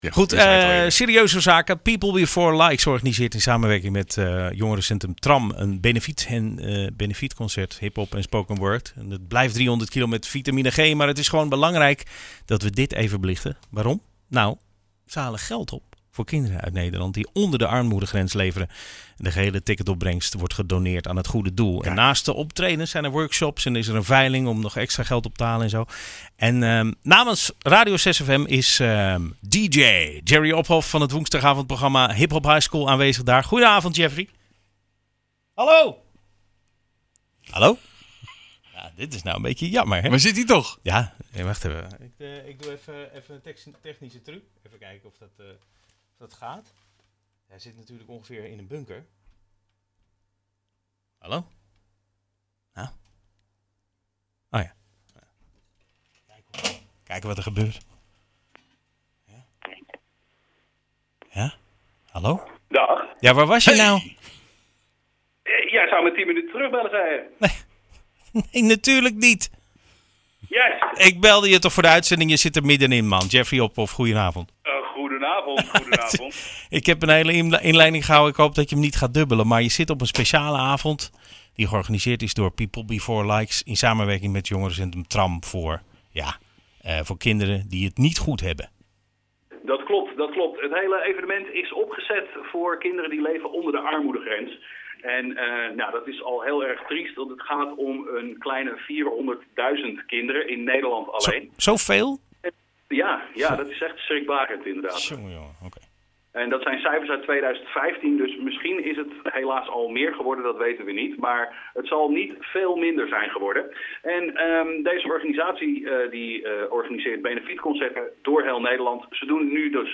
De avond duurde tot vrijdagavond 12 uur en komende woensdagavond zijn interviews te horen in het 6FM programma Hiphop Highscool tussen 7 en 9.